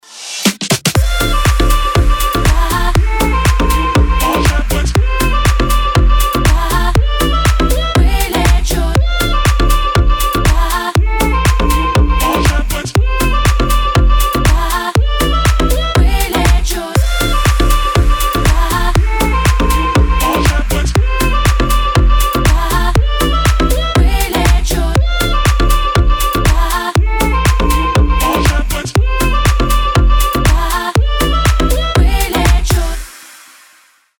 поп
dance
Club House